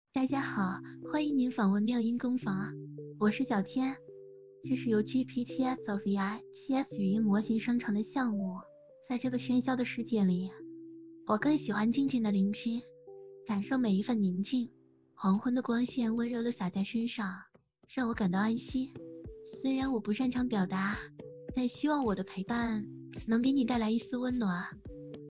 有声读物 小天少女音 GPT-SoVITS V2
该模型是搭配48K 适合有声读物 小天少女RVC模型
此模型噪音已经处理的很完美，后面我将会把音频处理的截图放在最后面，可供大家参考。
下面是推理后的效果